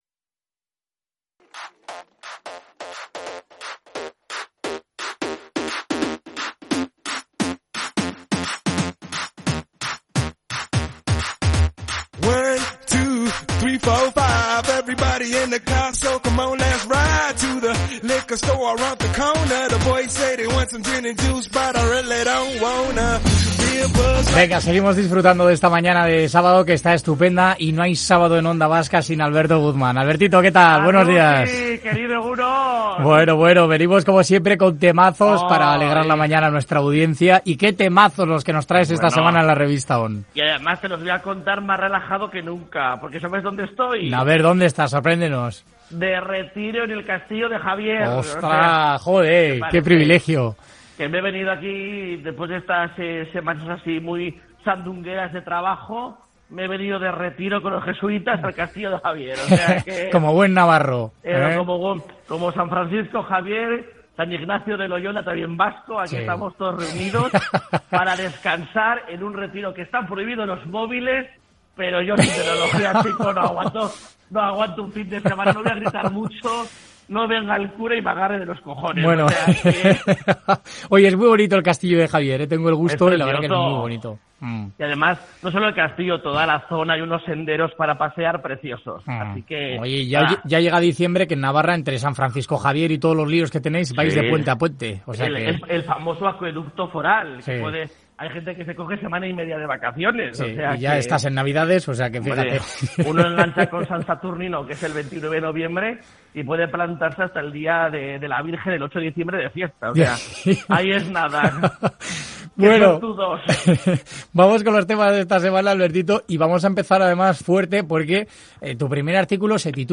nos habla de ello en la crónica social de la semana: